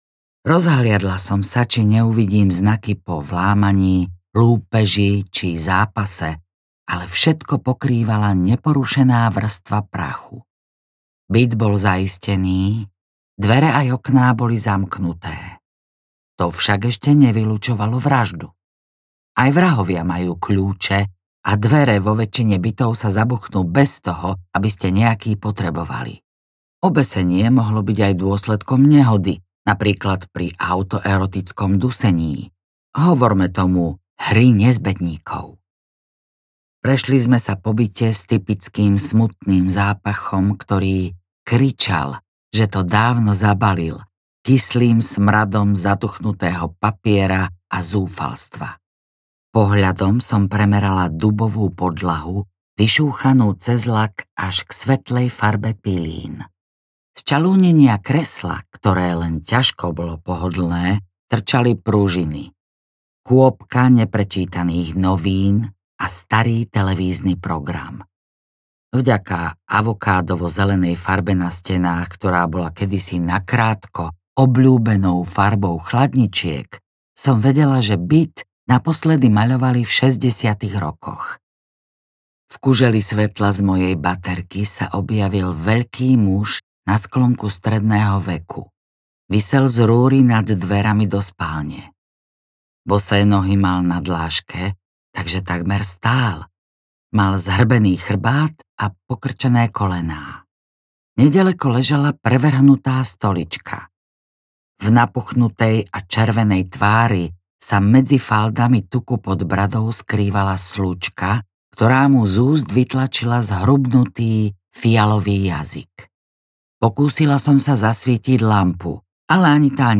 Svedectvá mŕtvych audiokniha
Ukázka z knihy